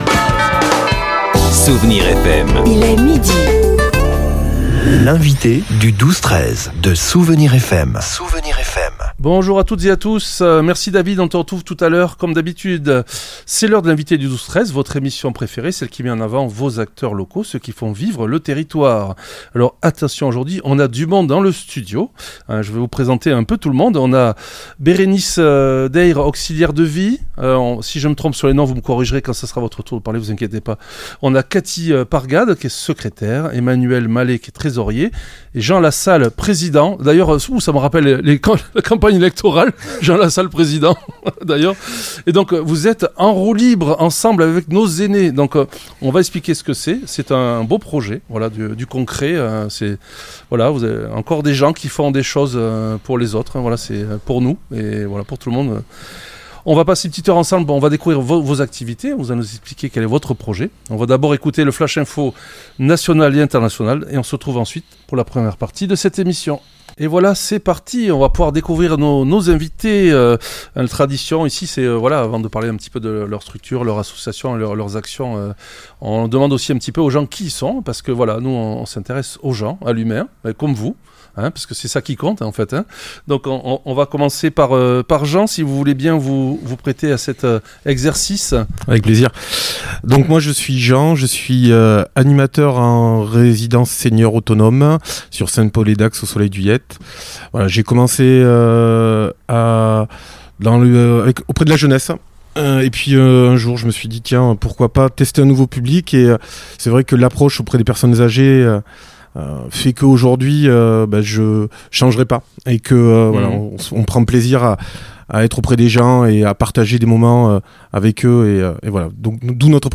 L’invité(e) du 12-13 recevait aujourd’hui l’équipe passionnée de l'association